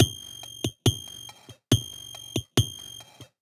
Gamer World Drum Loop 4.wav